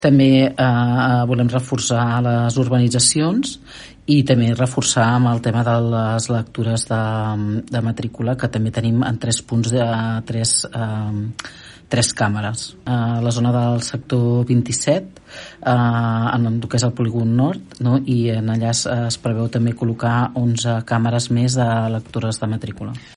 Susanna Pla, regidora de Governació de l’Ajuntament de Palafolls, explica el motiu de col·locar càmeres en aquests nous espais.